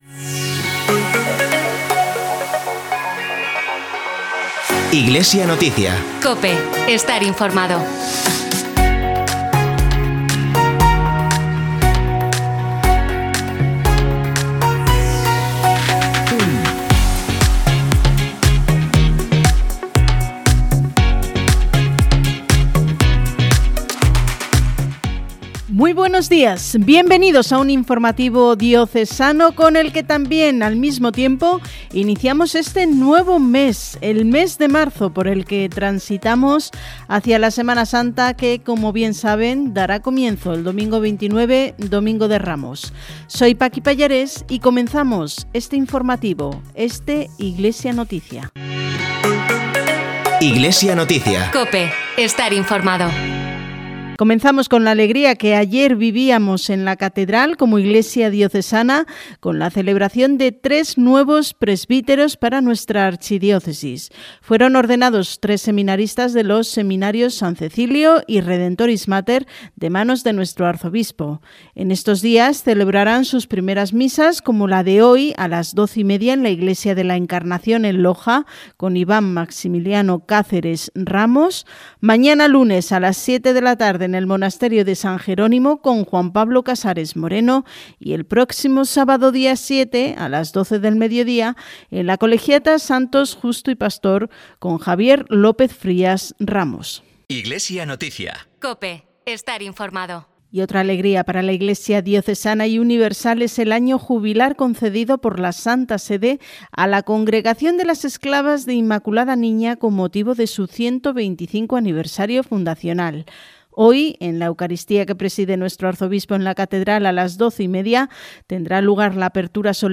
Programa emitido el 1 de marzo de 2026, en COPE Granada y COPE Motril.